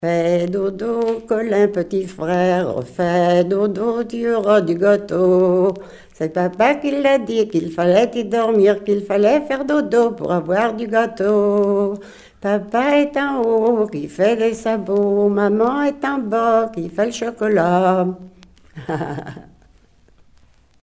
enfantine : berceuse
Pièce musicale éditée